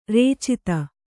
♪ rēcita